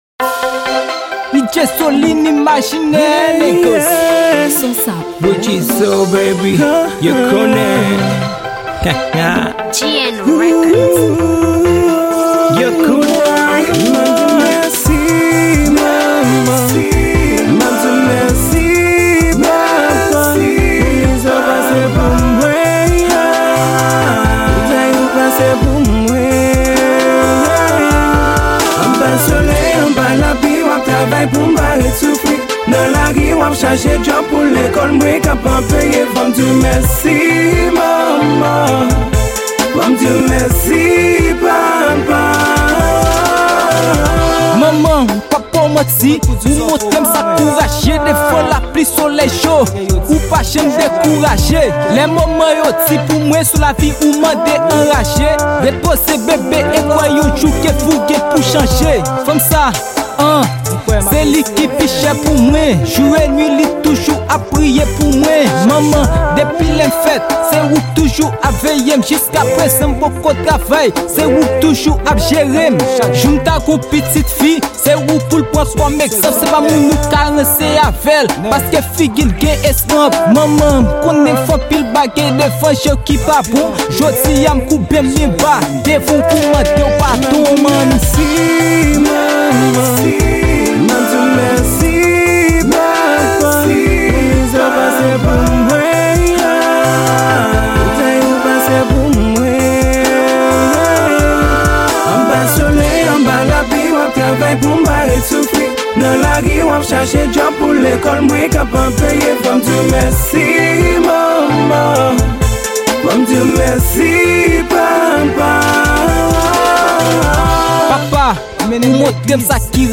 Genre : RAPP